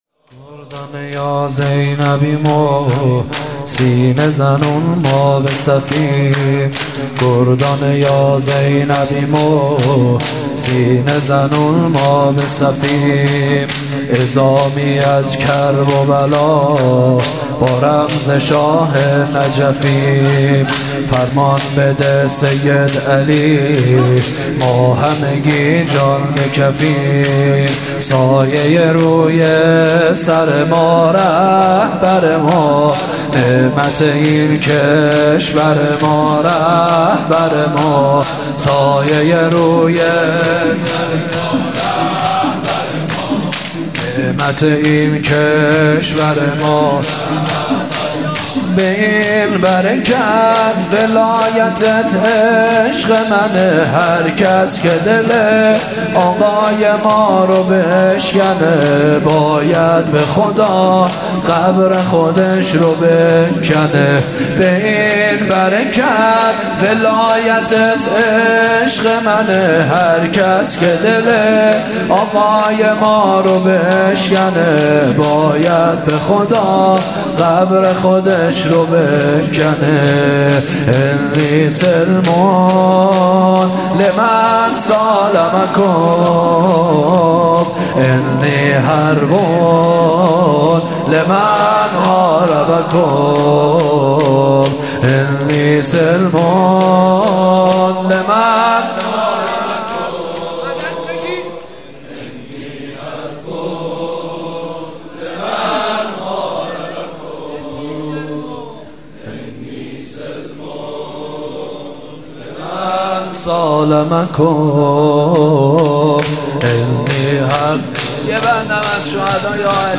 مراسم هفتگی 950425 شور ( گردان یا زینبیم
مراسم هفتگی